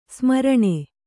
♪ smaraṇe